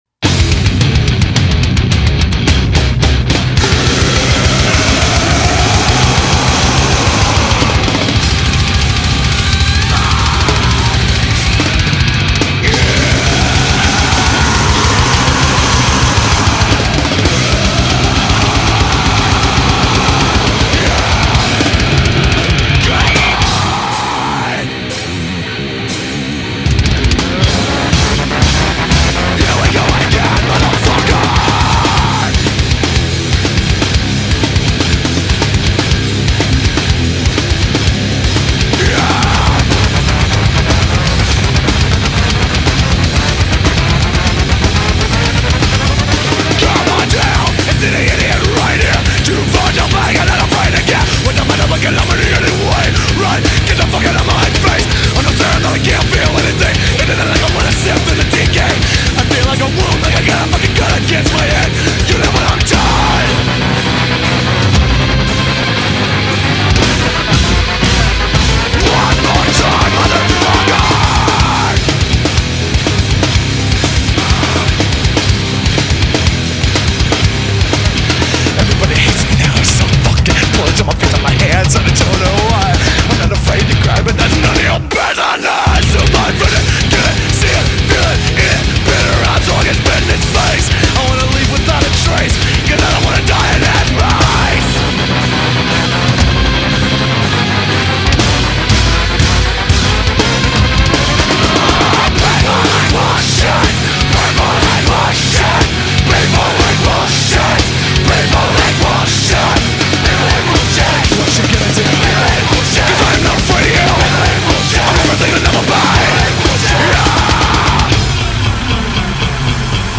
2025-01-03 17:35:22 Gênero: Rock Views